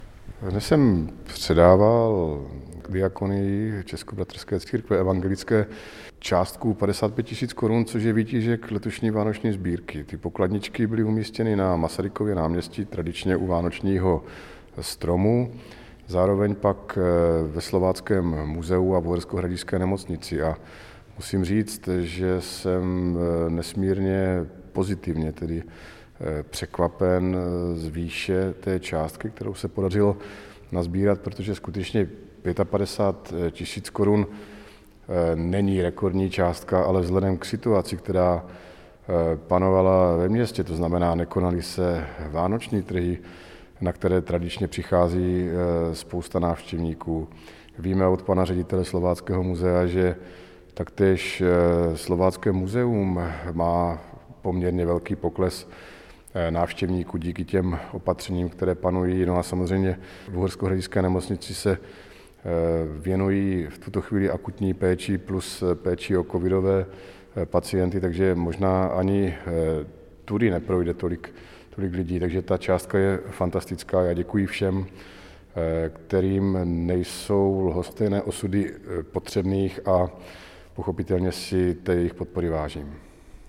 Starosta města Ing. Stanislav Blaha